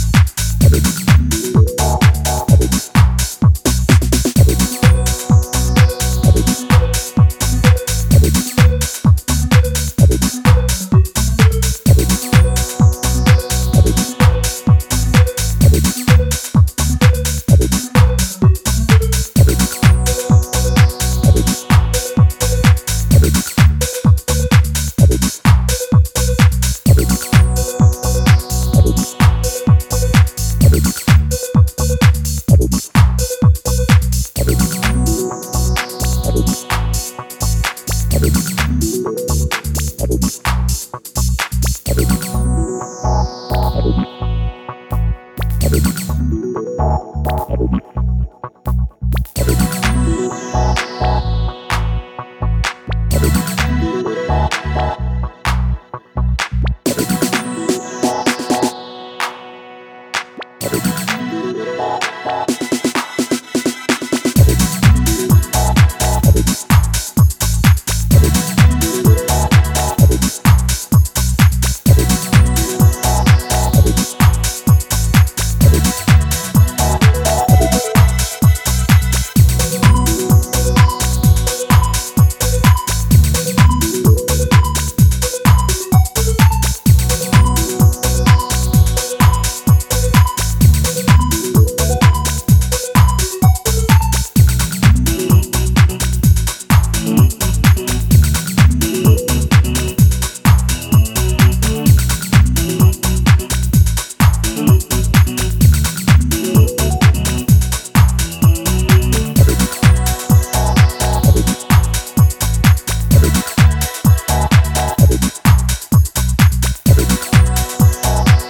blissed-out, iconic house